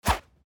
monster_atk_arrow_3.mp3